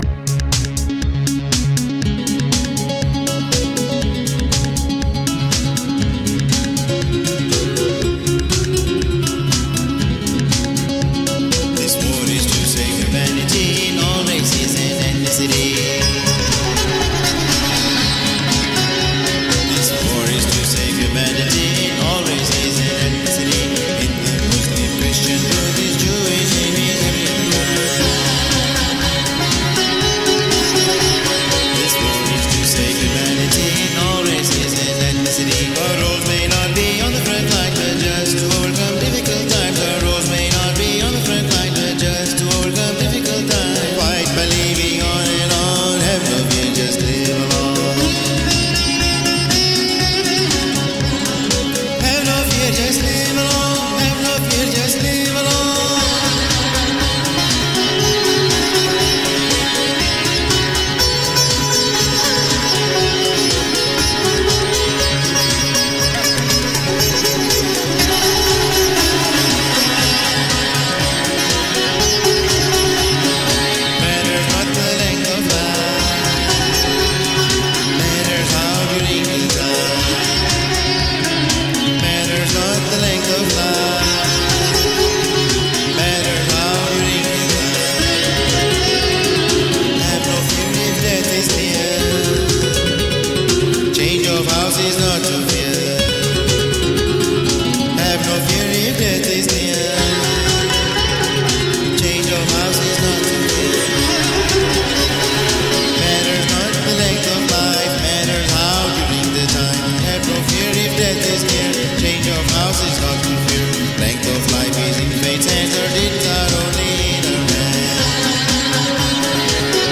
Electro indian sitar.
Tagged as: World, Indian, Indian Influenced, Sitar